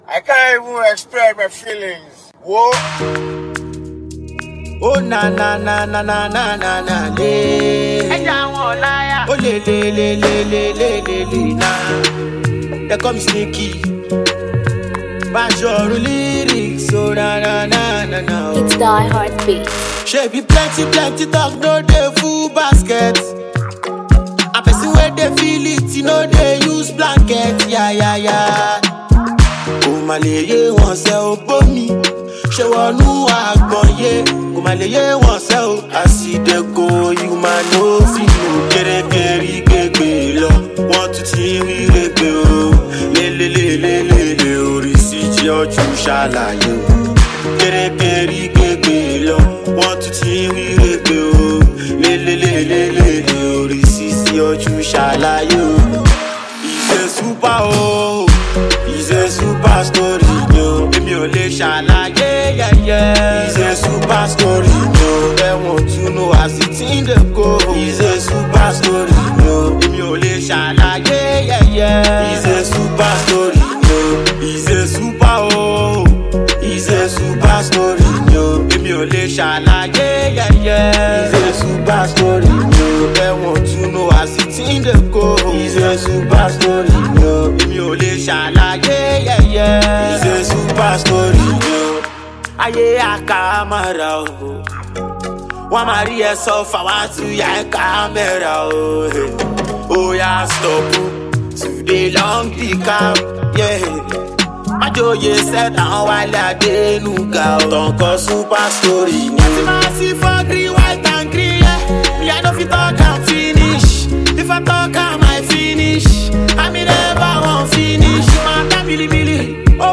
afro-fuji